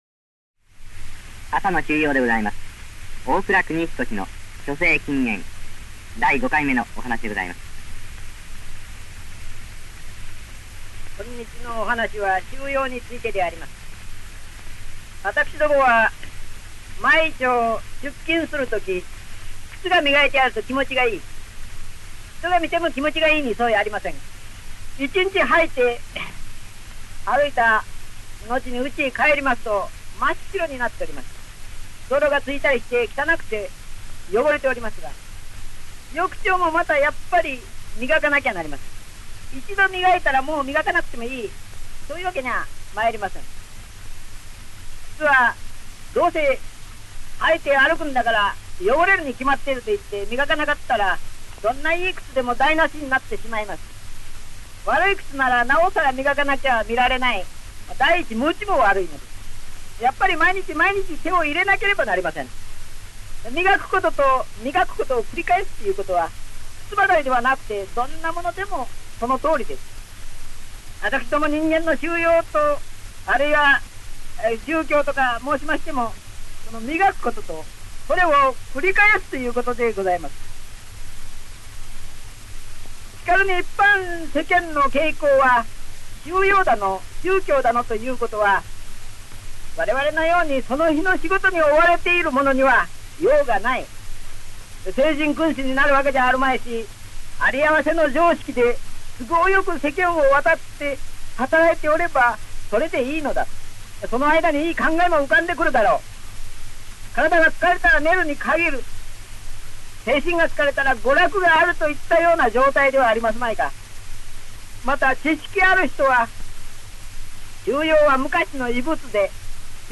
本音声は、その第5回目（昭和12年3月30日）のラジオ放送を録音したSP盤レコードをデジタル化したものです。